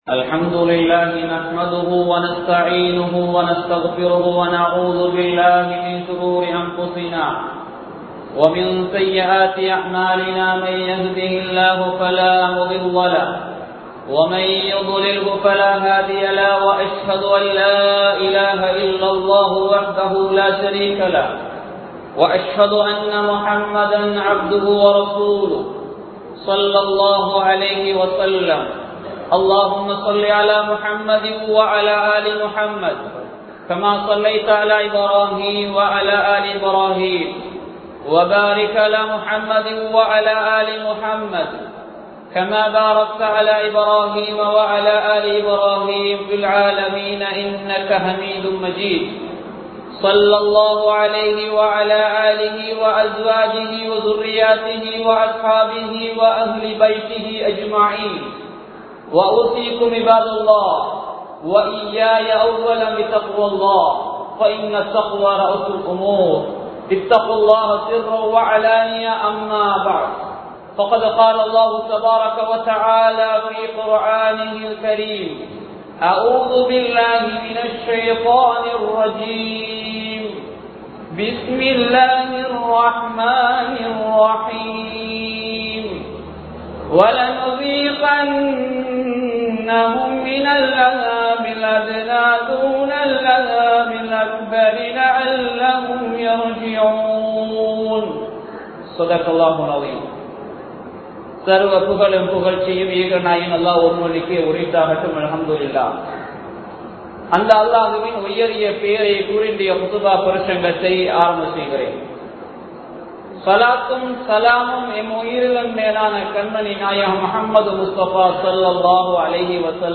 அல்லாஹ்வை நெருங்குவோம் | Audio Bayans | All Ceylon Muslim Youth Community | Addalaichenai
Colombo 10, Maligawatttha, Grand Jumua Masjidh